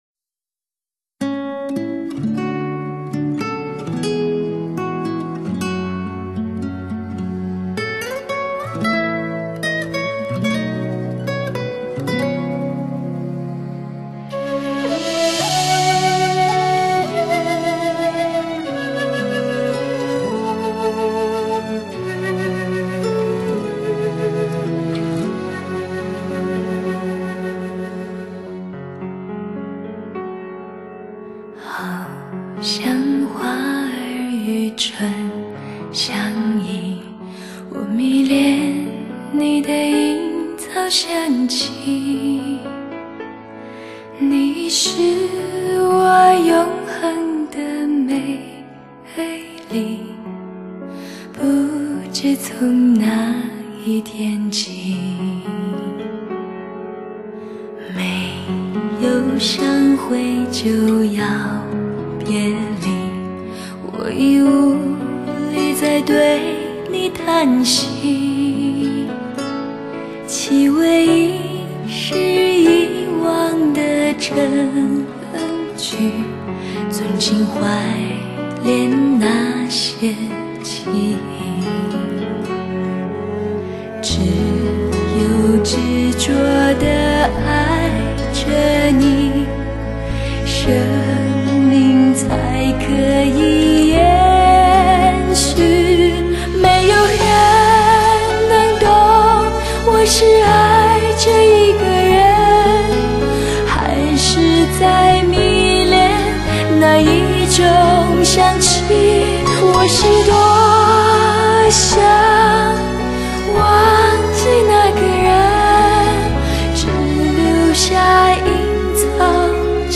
最浪漫感性的都市情歌,送给每一个有故事的你....
细听,歌声悠然,唤起藏匿心底的丝丝记忆,反复播映;